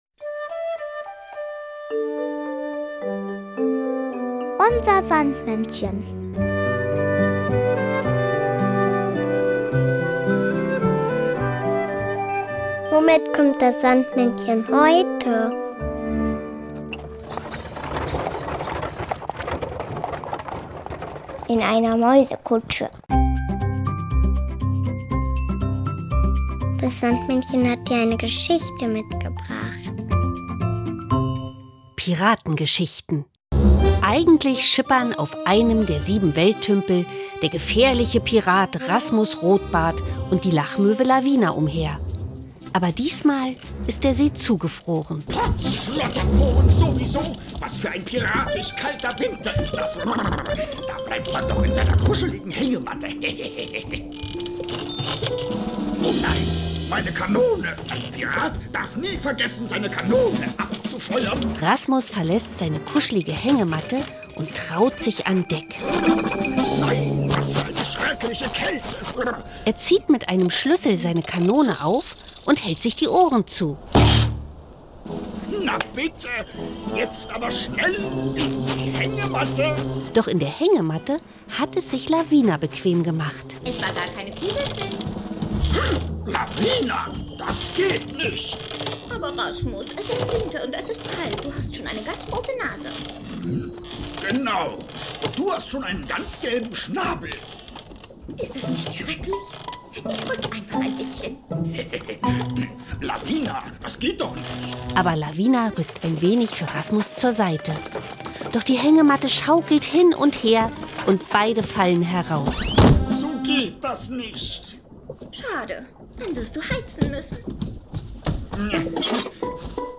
Und das Beste: Man hört, mit welchem Fahrzeug das Sandmännchen heute vorbeikommt! UNSER SANDMÄNNCHEN hat aber nicht nur zauberhafte Hörspiele dabei, sondern auch noch ein passendes Lied und den berühmten Traumsand.